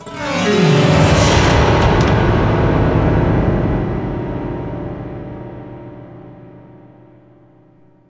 SI2 PIANO0CR.wav